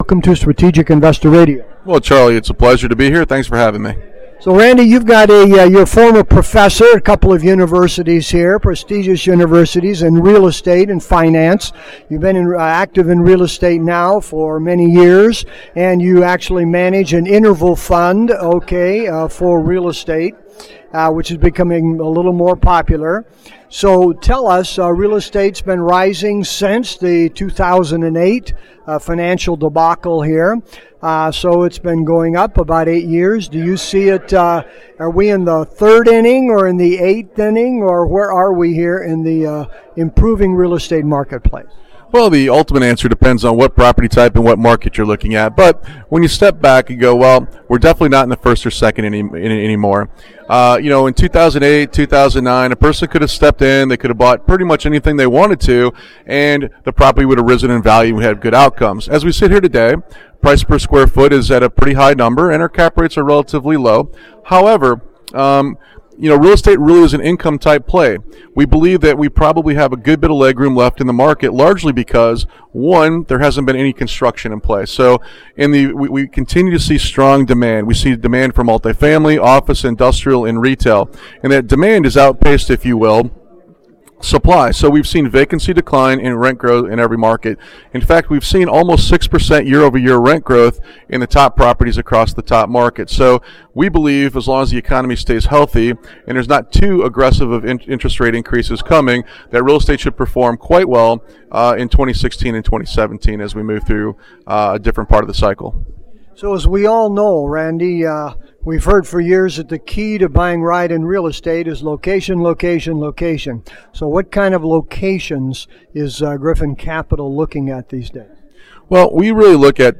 This interview was done at the ADISA Conference in San Diego.